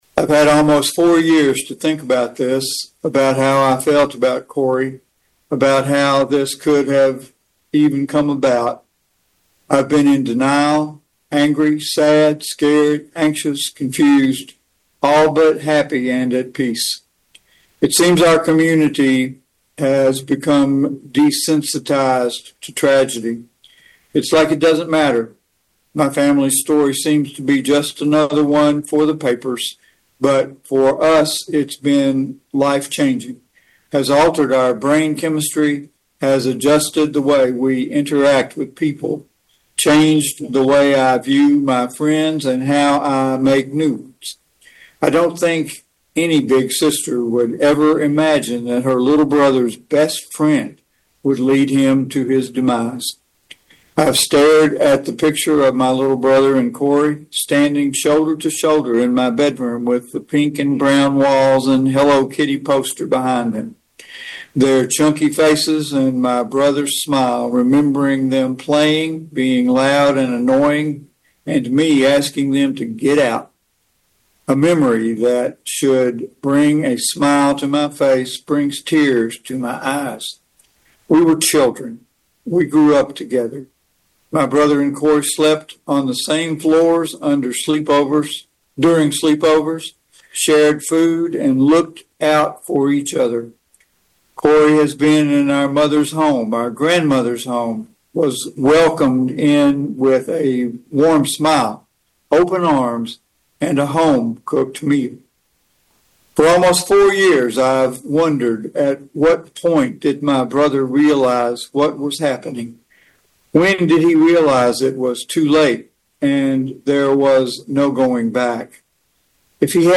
FULL IMPACT STATEMENT: